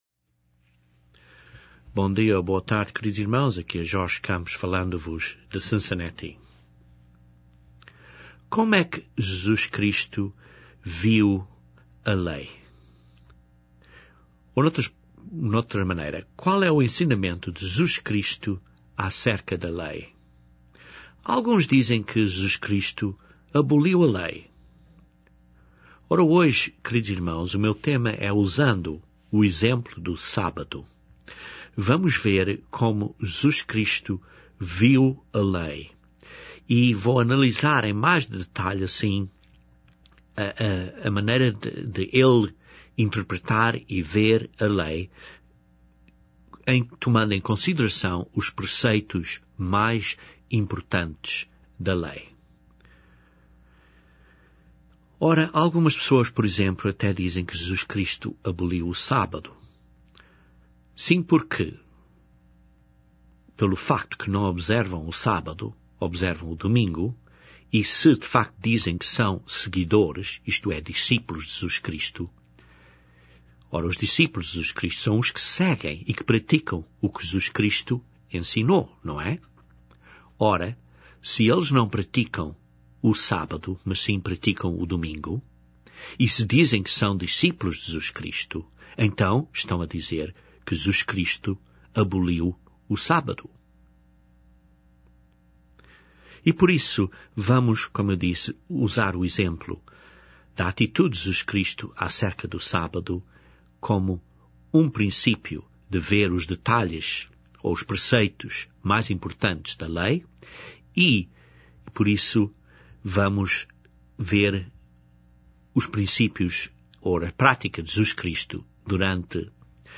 Este sermão, usando o exemplo da lei do Sábado, demonstra como estes princípios são postos em prática.